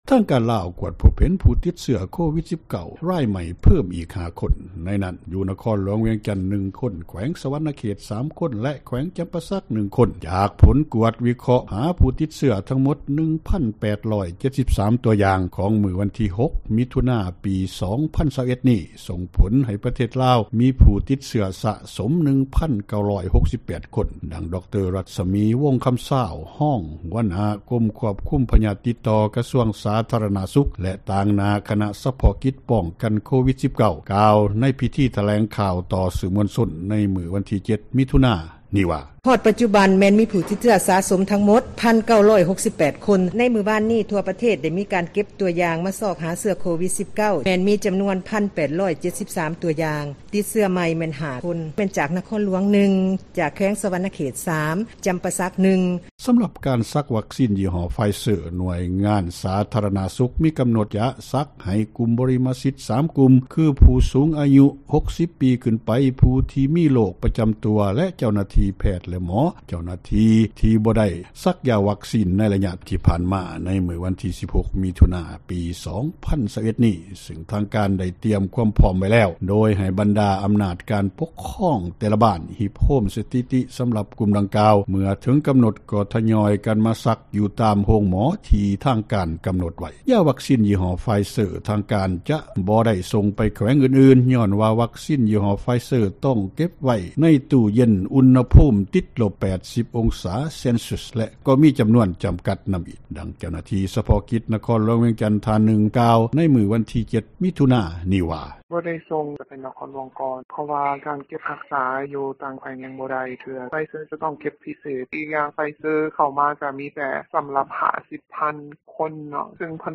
ກ່ຽວກັບເຣຶ້ອງນີ້ ຊາວບ້ານຜູ້ທີ່ມີອາຍຸ ຫຼາຍກວ່າ 60 ປີ ທ່ານນຶ່ງເວົ້າວ່າ ກໍຢາກສັກວັກຊິນ ຍີ່ຫໍ້ໄຟເຊີ ເພາະມັນດີກວ່າຍີ່ຫໍ້ອື່ນ ທີ່ບໍ່ມີ ຜົລຂ້າງຄຽງ ແລະກໍຕຽມພ້ອມແລ້ວ ຍ້ອນມີໂຣຄ ປະຈຳໂຕ.
ຜູ້ສູງອາຍຸອີກຄົນນຶ່ງ ກ່າວວ່າລາວໄດ້ກະກຽມຕົວເອງແລ້ວ. ຖ້າຫາກໄປສັກວັກຊີນ ໄຟເຊີ ຕ້ອງໄດ້ຕຽມໂຕ ແລະ ພັກຜ່ອນເພື່ອສ້າງ ພູມຕ້ານທານ.